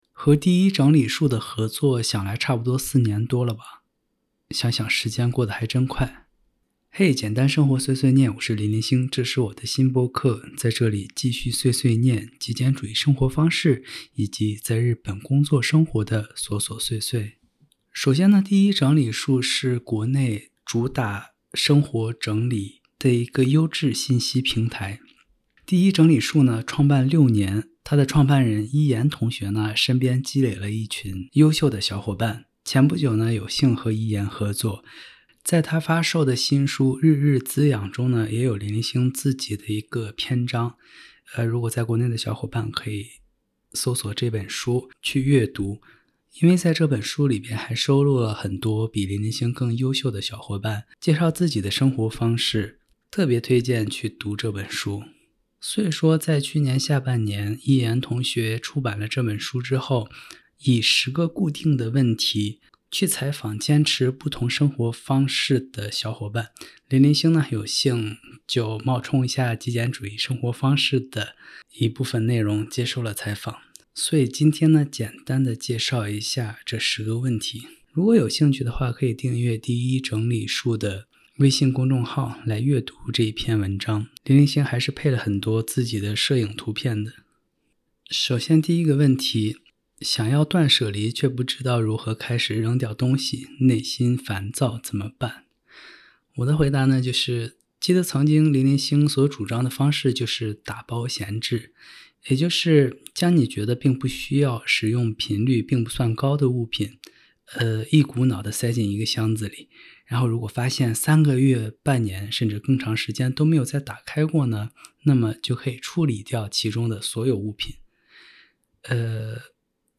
接受第1整理术采访的10个Q&A